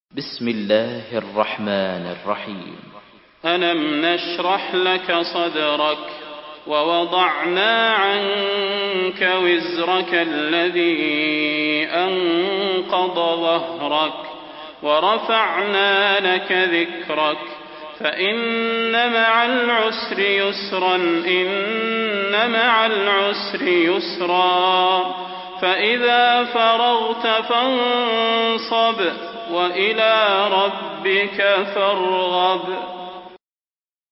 Surah আশ-শারহ MP3 by Salah Al Budair in Hafs An Asim narration.